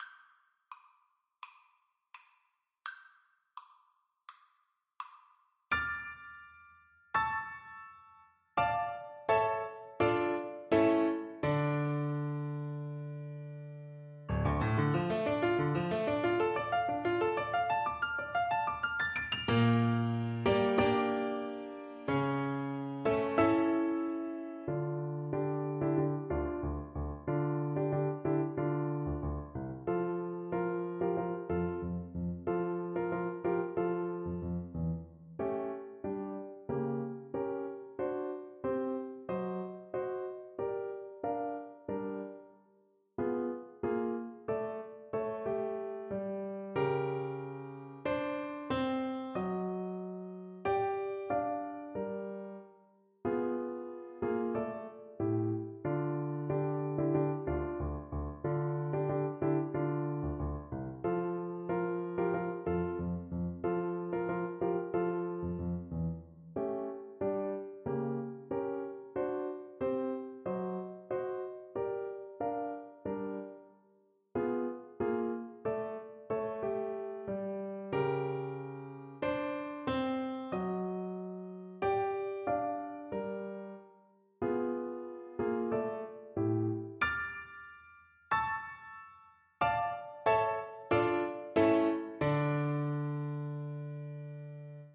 Free Sheet music for Flute
Flute
Allegro molto moderato = 84 (View more music marked Allegro)
D minor (Sounding Pitch) (View more D minor Music for Flute )
4/4 (View more 4/4 Music)
D5-D7
Classical (View more Classical Flute Music)